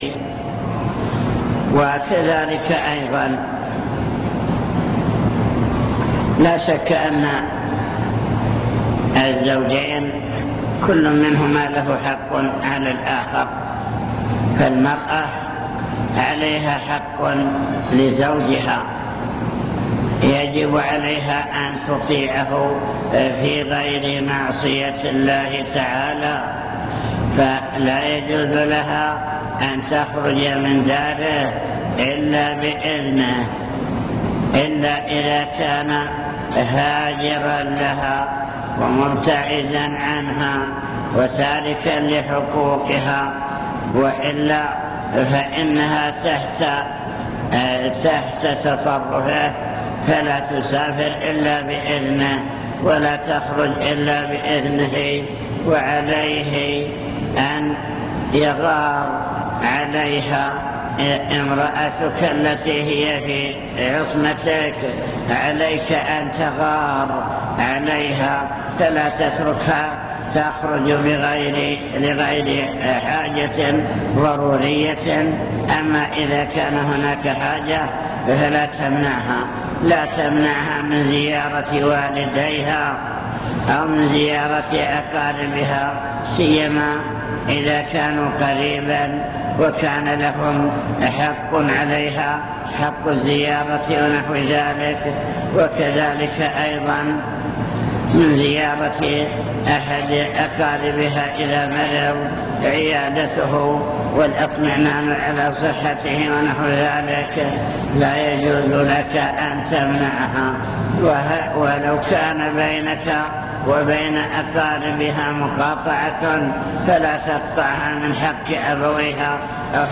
المكتبة الصوتية  تسجيلات - محاضرات ودروس  محاضرة حول توجيهات في العقيدة والأسرة